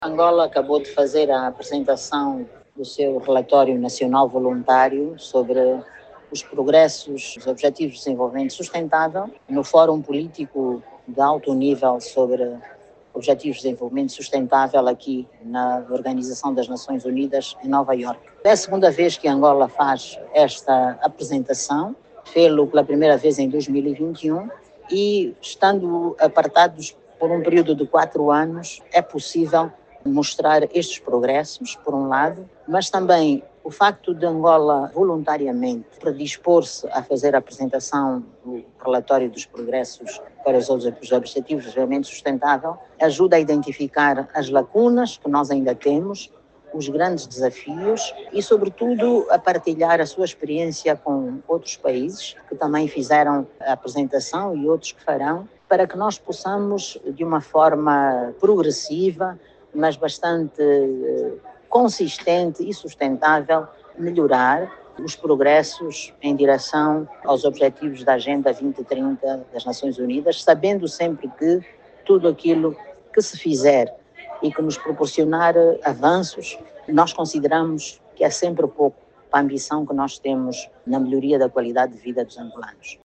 A Ministra de Estado para a Área Social, Maria do Rosário Bragança, apresentou na sexta-feira(18), na sede da ONU, em Nova Iorque, o 2º Relatório Nacional Voluntário de Angola sobre a implementação da Agenda 2030, reiterando o  compromisso internacional com o cumprimento dos Objectivos de Desenvolvimento Sustentável, para não deixar ninguém para trás.